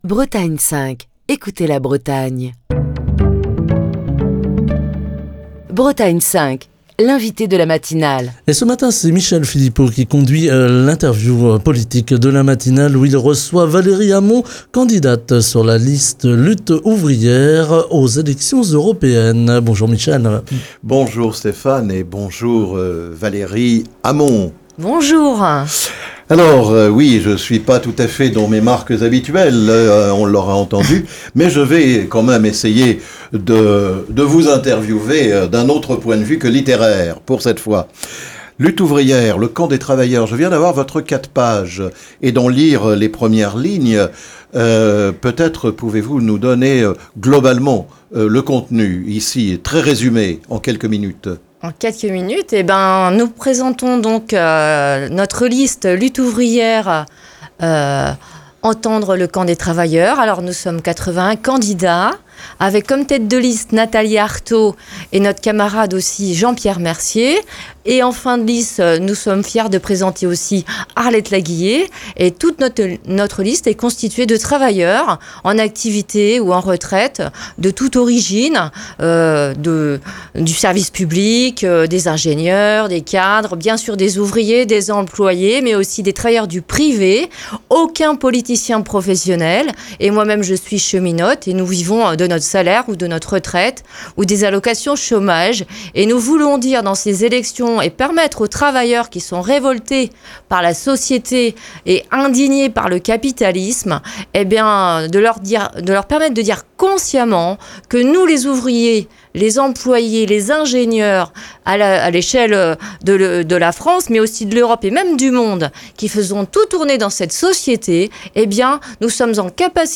est l'invitée de la matinale de Bretagne 5